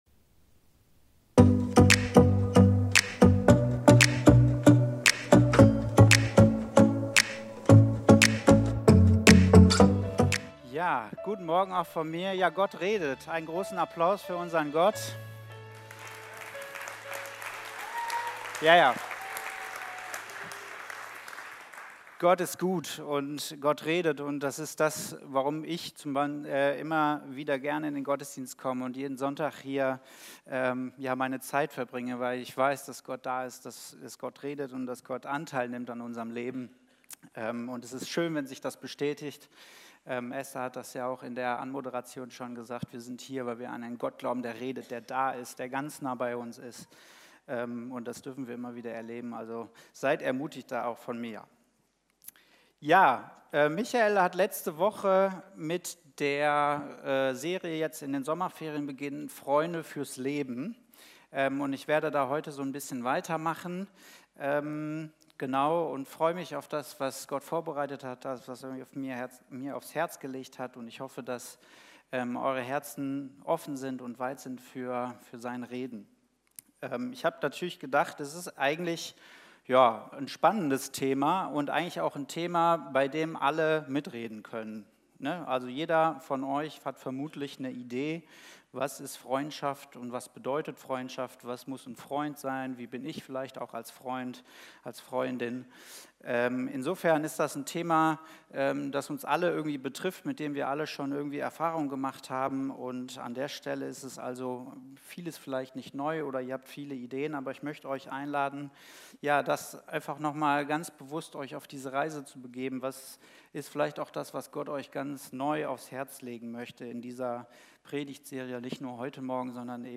Video und MP3 Predigten
Kategorie: Sonntaggottesdienst Predigtserie: Freunde fürs Leben - Wie gute Beziehungen gelingen